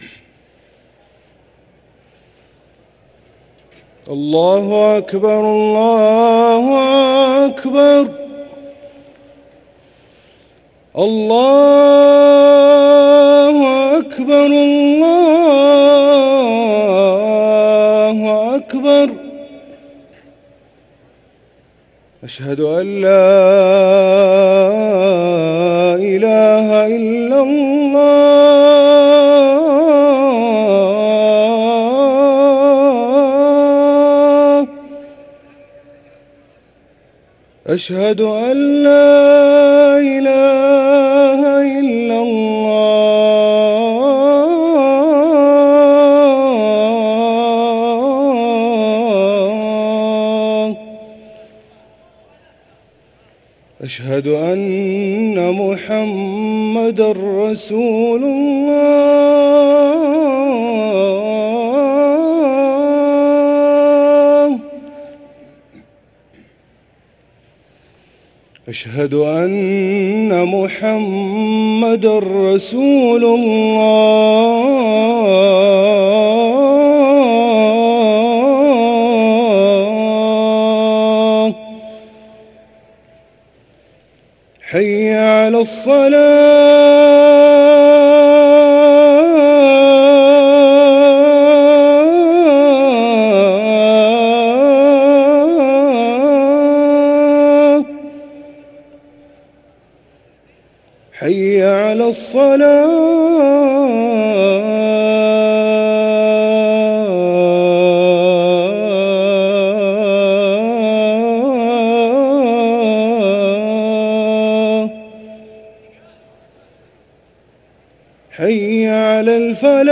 أذان الفجر
ركن الأذان